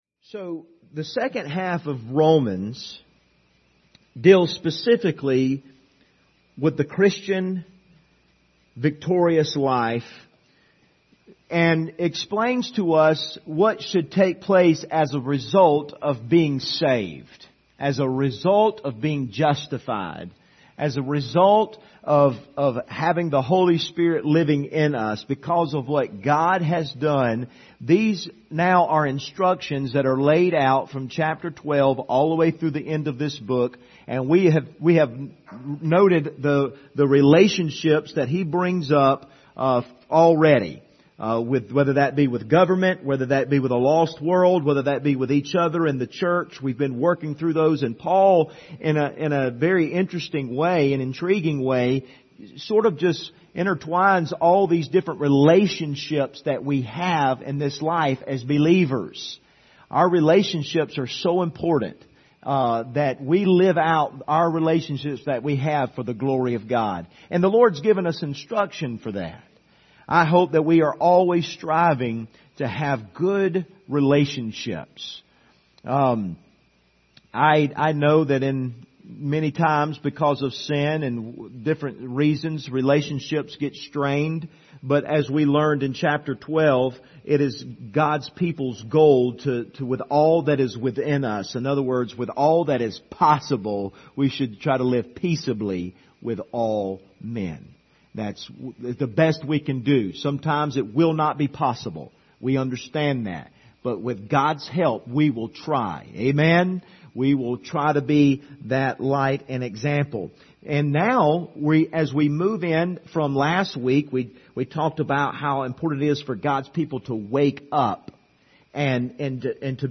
Passage: Romans 14:1-8 Service Type: Sunday Evening